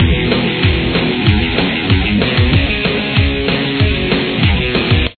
Verse Riff